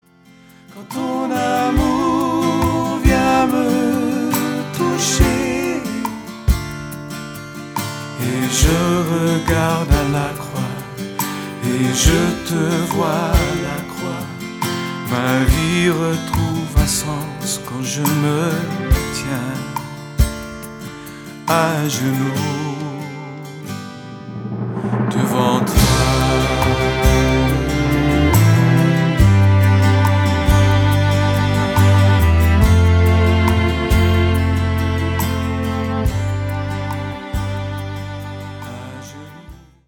allant du pop au rock en passant par des ballades douces